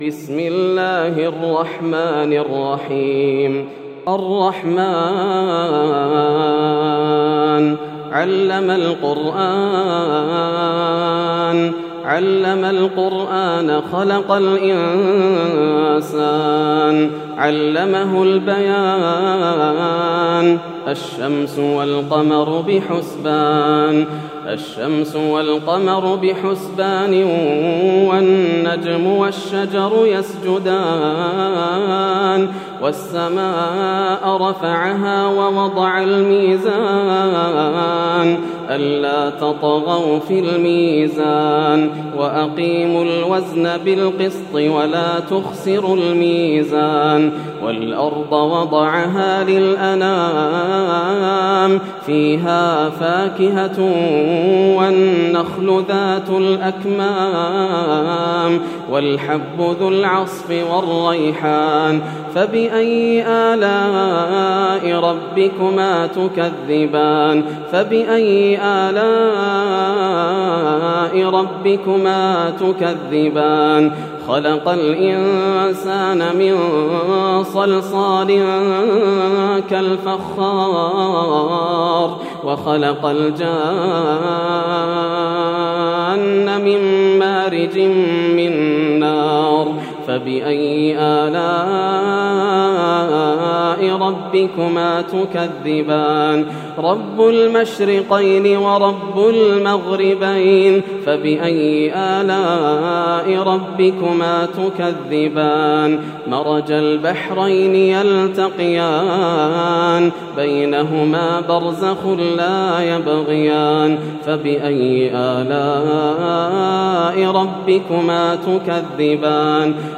سورة الرحمن > السور المكتملة > رمضان 1431هـ > التراويح - تلاوات ياسر الدوسري